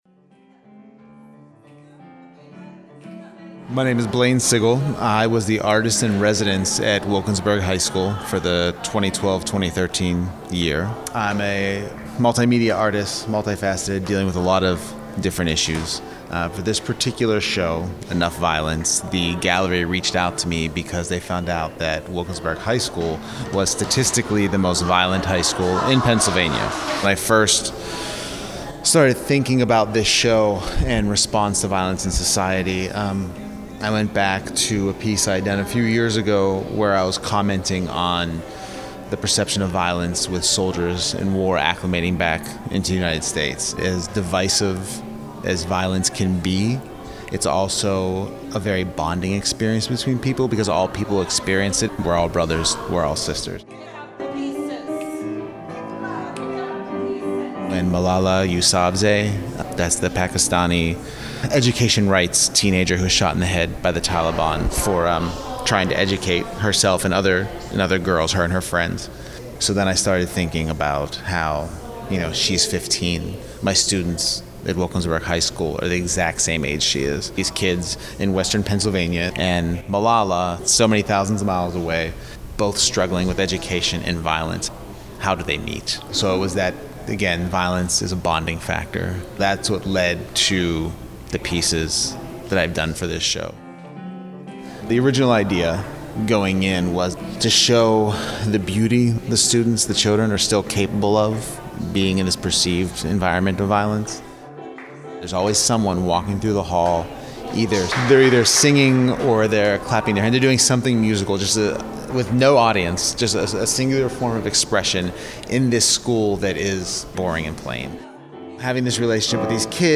ENOUGH Violence: Artists Speak Out opening reception
Hear visitors, artists and Contemporary Craft staff react to the art and the exhibition below.